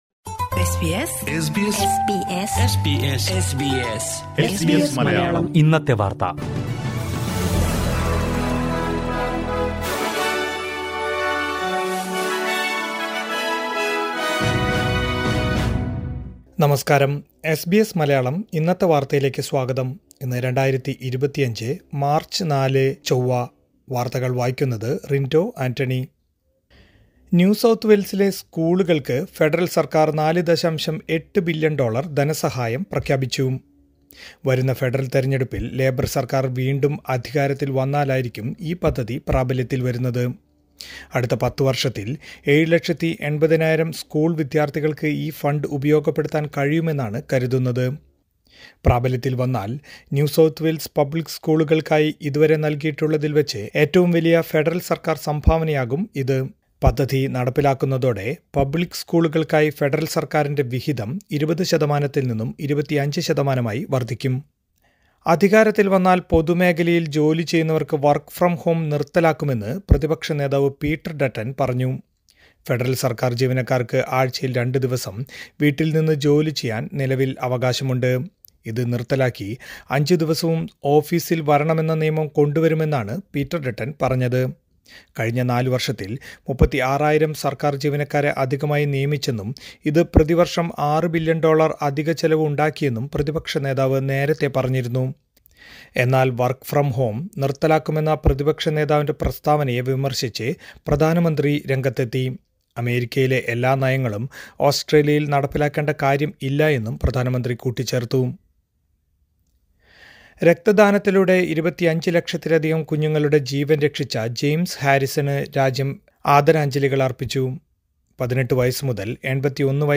2025 മാർച്ച് നാലിലെ ഓസ്‌ട്രേലിയയിലെ ഏറ്റവും പ്രധാന വാര്‍ത്തകള്‍ കേള്‍ക്കാം...